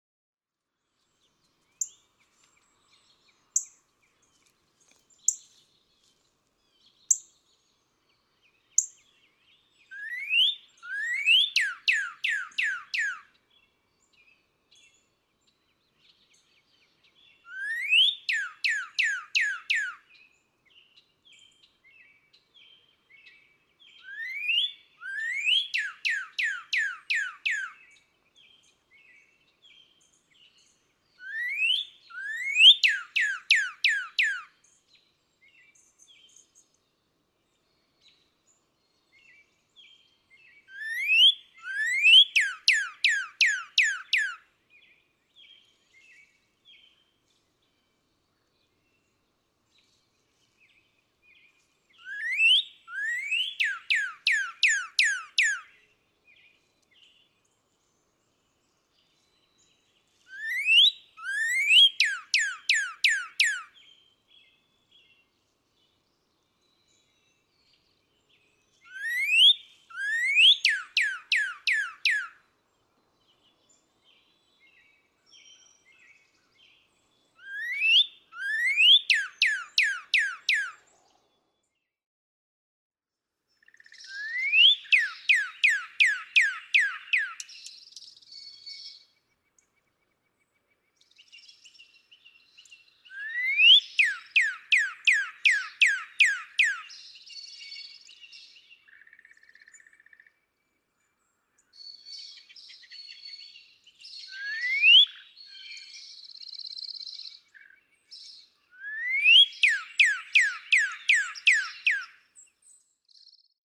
♫58. Later the same morning, she calls and sings again, the male nearby (beginning at 1:23) offering the same song. April 9, 2017. Hatfield, Massachusetts. (1:53)
058_Northern_Cardinal.mp3